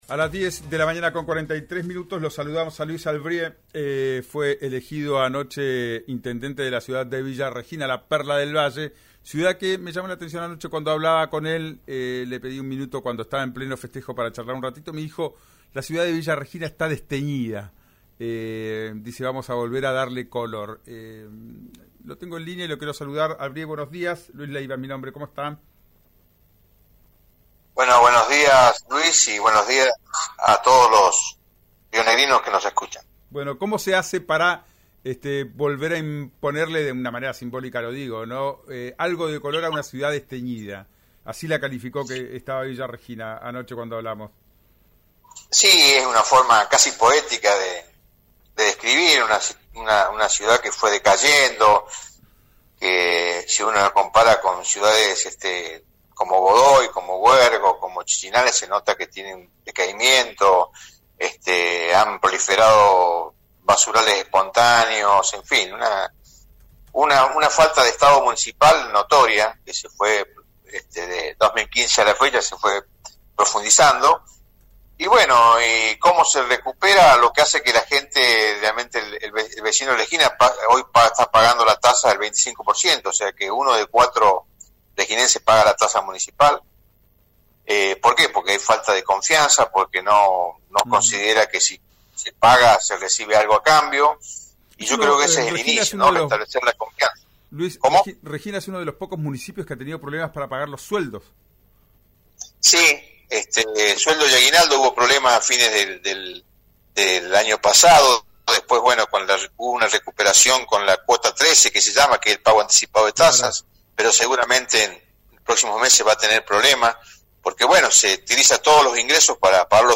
Luis Albrieu, intendente electo en Villa Regina